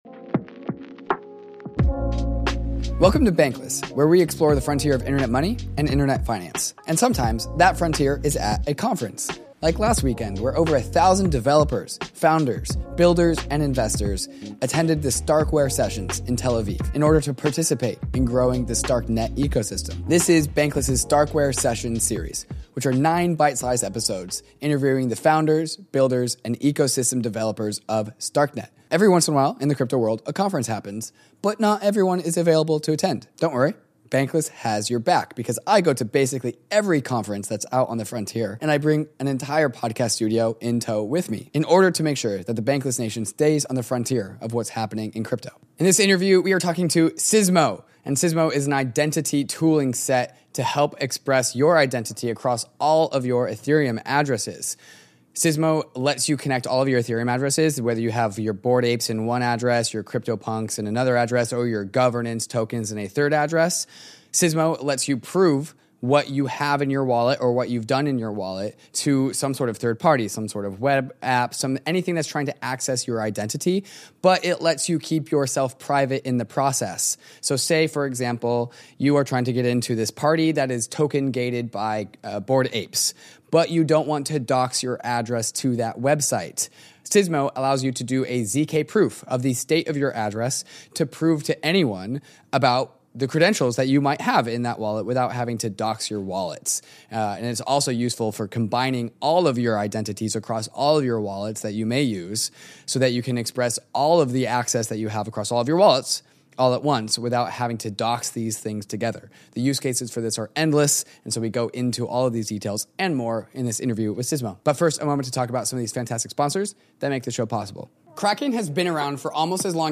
Sometimes, the frontier is at a crypto conference.
We’re returning from our adventures in Tel Aviv with nine exclusive interviews with some of the key players in the StarkNet space.